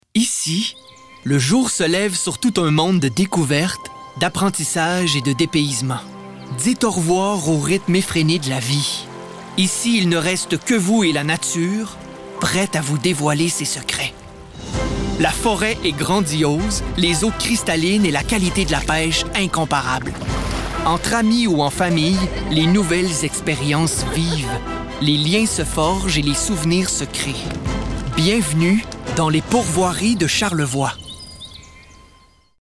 Voix Hommes | Agence M